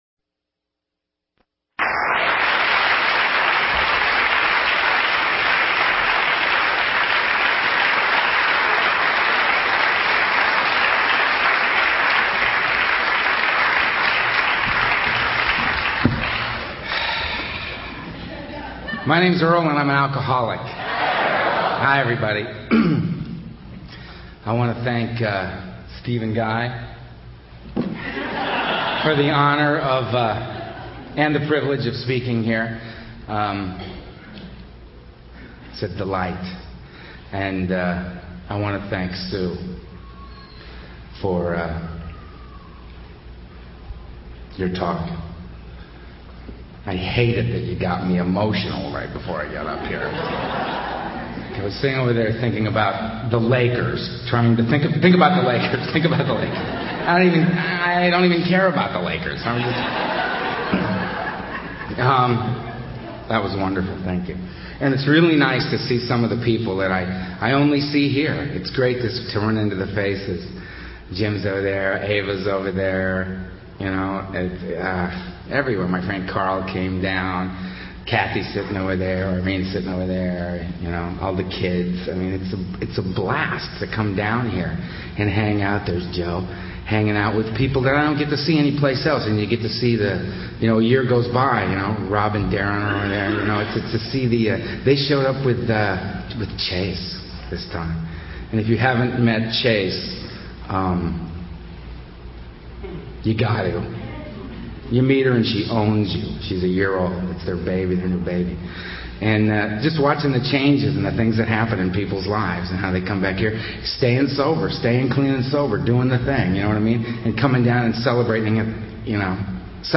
Club Med Playa Blanco Conference; Playa Blanco Mexico; 1998 | AA Speakers